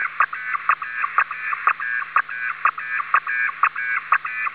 MERLIN/ALIS-2/RS-ARQ /RS ARQ 240 This is the 240 bd 8 tone burst ARQ mode used in the Rohde & Schwarz MERLIN modem. When the system is found in the 7 tone mode it is in ISS mode, the IRS mode uses an 8 tone signal. Both will be measured as 240 bd (720 bits/sec) with tones shifted by 240Hz.